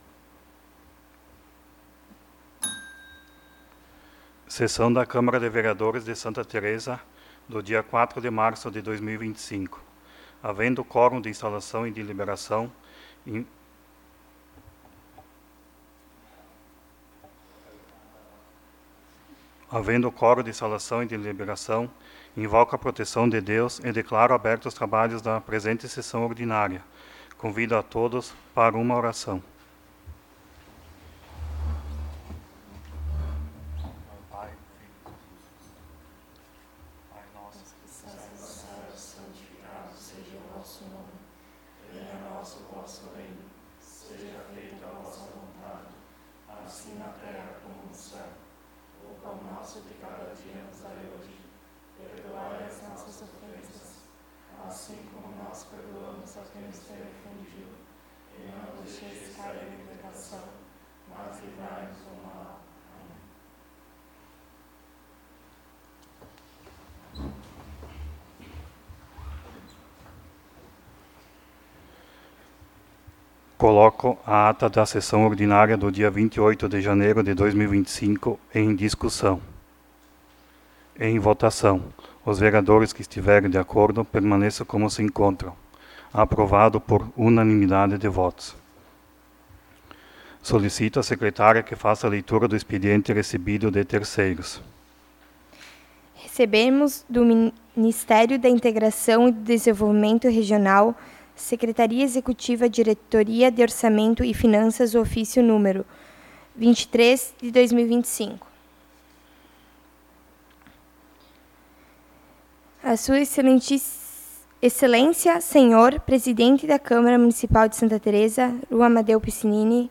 Local: Câmara Municipal de Santa Tereza